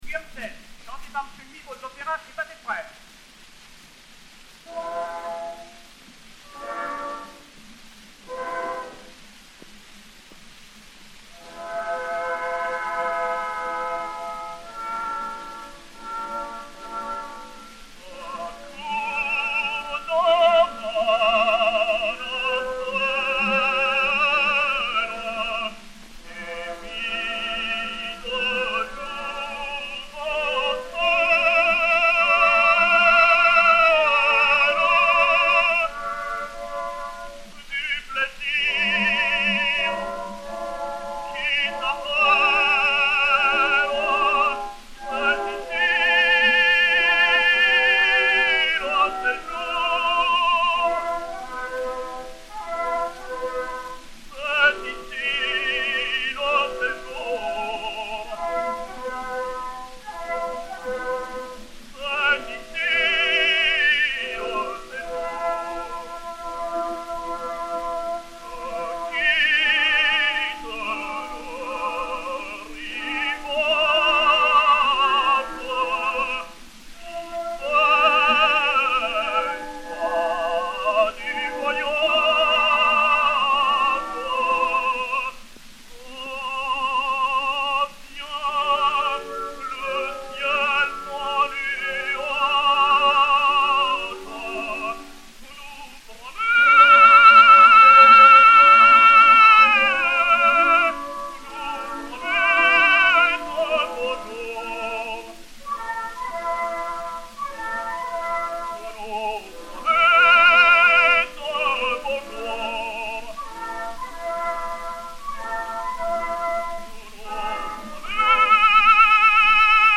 et Orchestre
Pathé saphir 90 tours 4579, enr. à Paris en 1906/1907